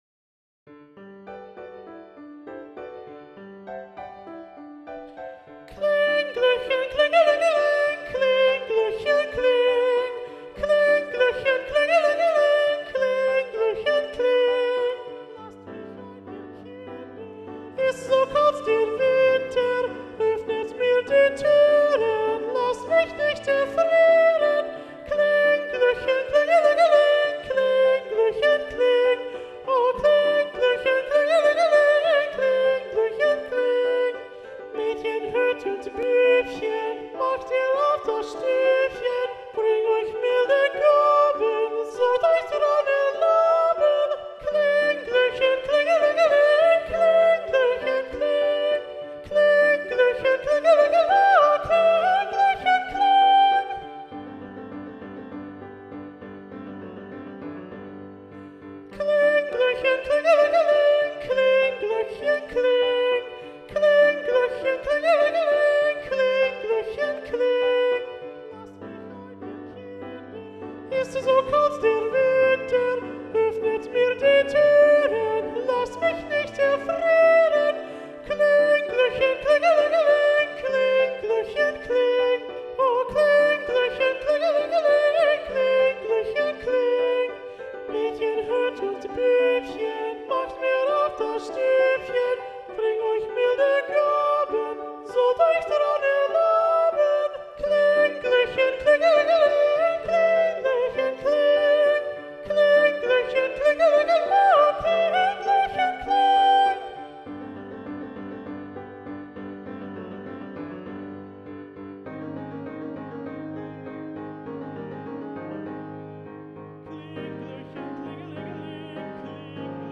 – Soprano 1 Predominant – arr. Robert Sieving